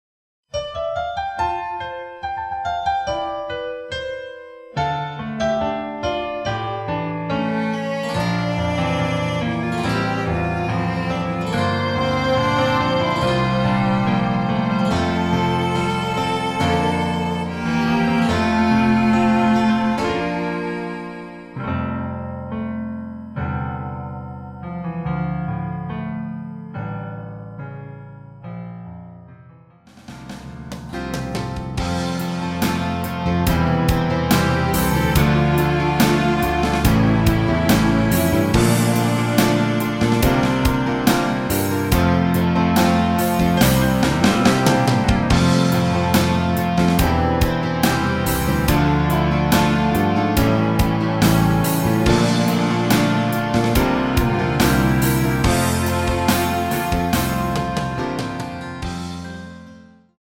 키 F 가수
원곡의 보컬 목소리를 MR에 약하게 넣어서 제작한 MR이며